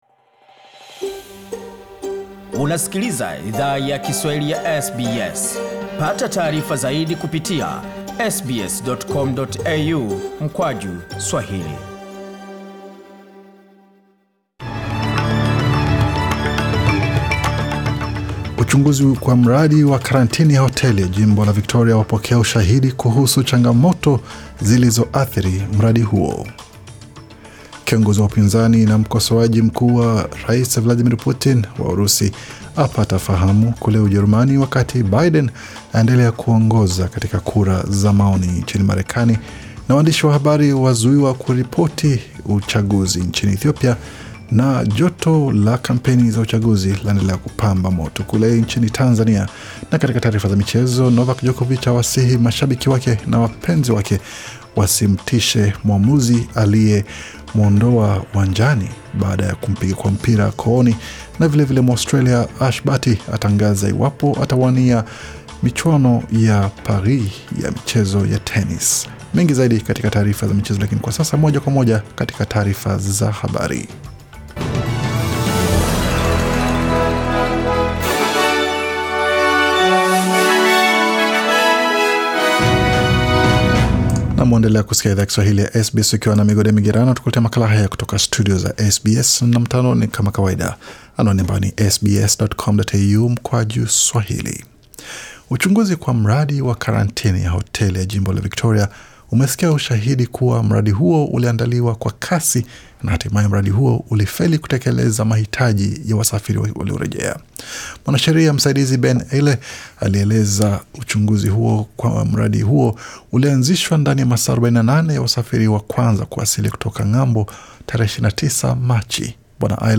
Taarifa ya habari 8 Septemba 2020